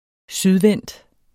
sydvendt adjektiv Bøjning -, -e Udtale [ -ˌvεnˀd ] Betydninger som vender mod syd Her er skøn gårdhave og stor, sydvendt terrasse 1992 Jn: JN Ejendomsavis, 1992.